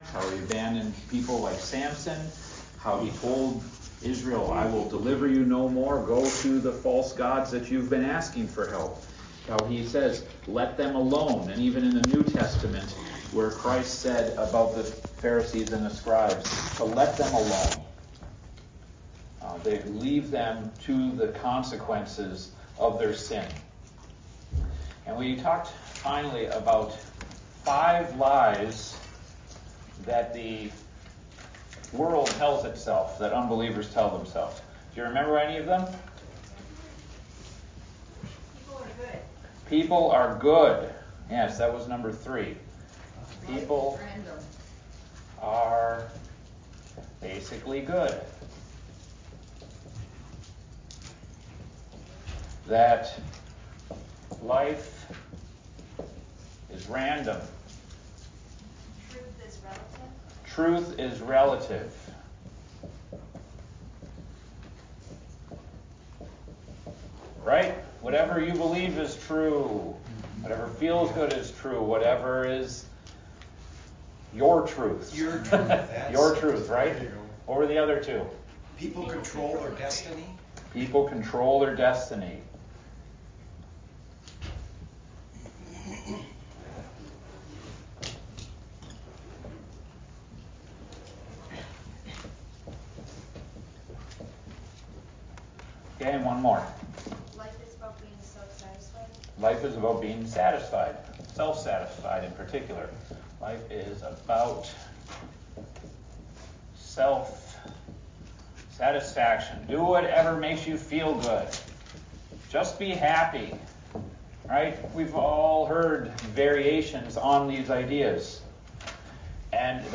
Adult Sunday School A Study in Revelation